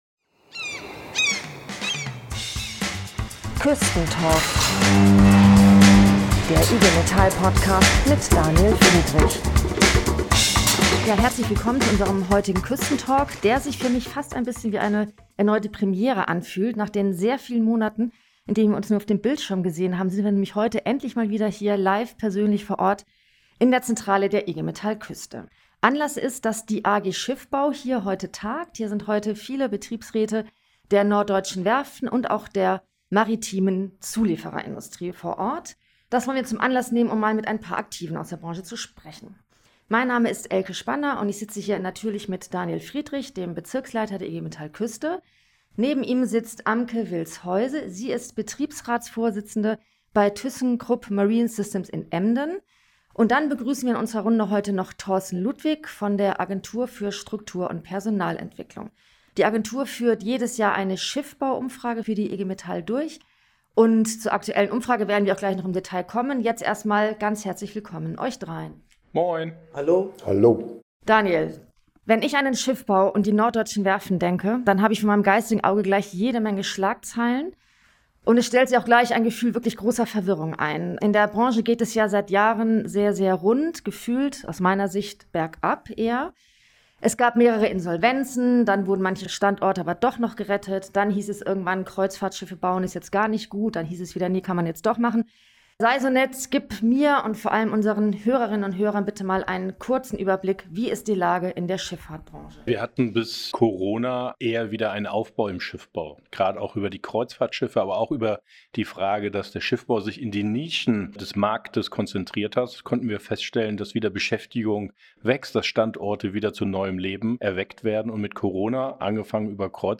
Die Podcast-Aufnahme fand dieses Mal im Rahmen der Sitzung der AG Schiffbau in Hamburg statt.